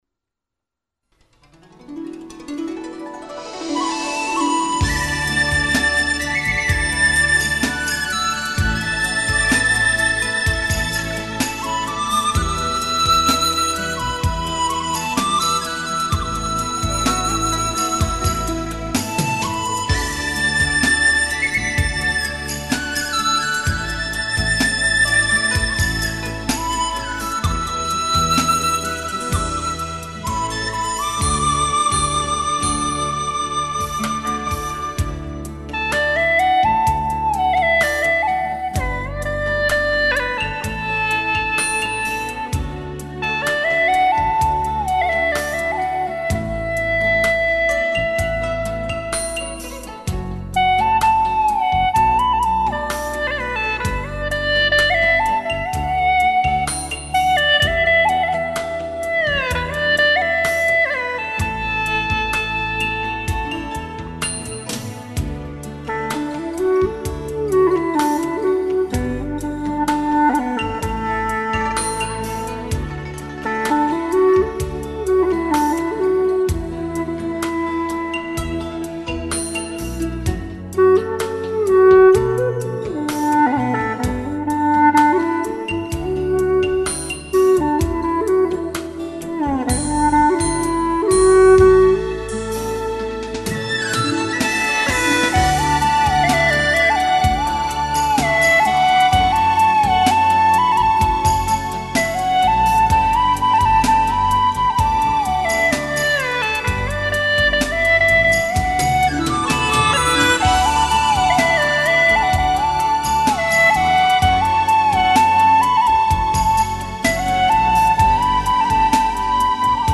调式 : D 曲类 : 流行
【大小D调】 我要评论